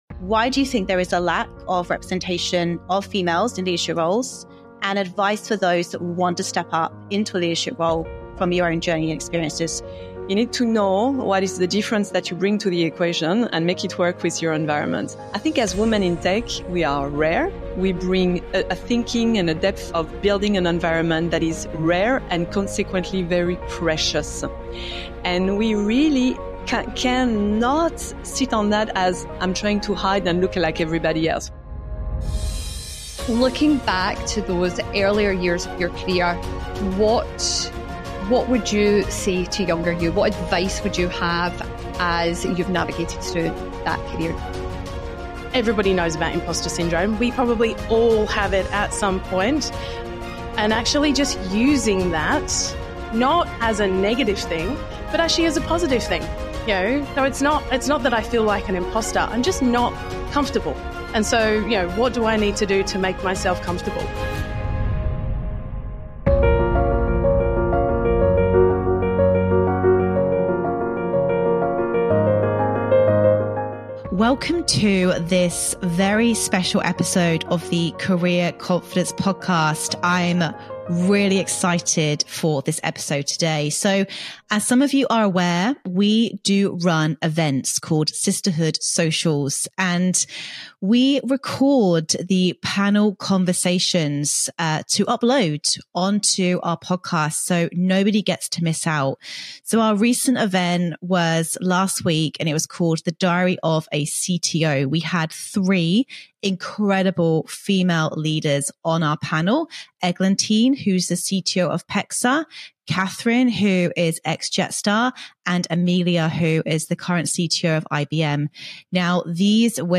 We are excited to share the recording of our recent Sisterhood Club Social event, The Diary of a CTO.
We were extremely grateful to have 3 exceptional Chief Technology Officers speak on career confidence and staying adaptable to tech trends.